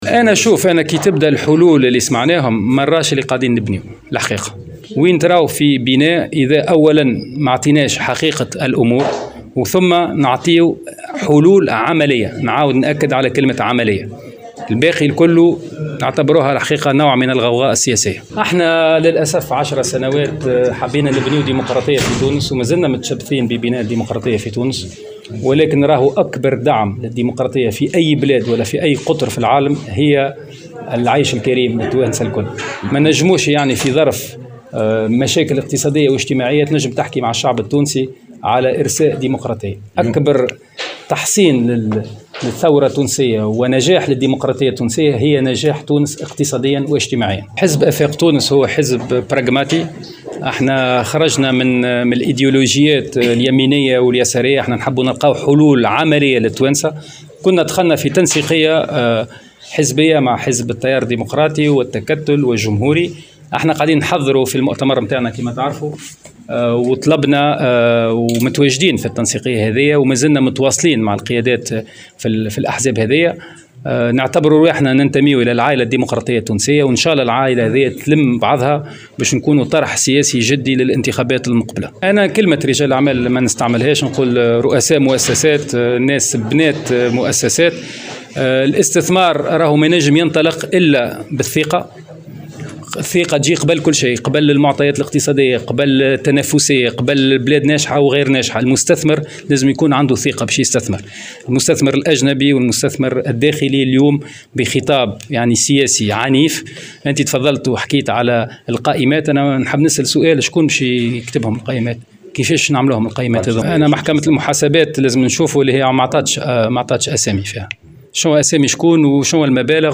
وصف رئيس حزب آفاق تونس فاضل عبد الكافي، في تصريح لمراسل الجوهرة أف أم، اليوم الخميس، المقترحات التي قدمها رئيس الجمهورية قيس سعيد، لحل المشاكل الاقتصادية في البلاد، بأنها "غوغاء سياسية".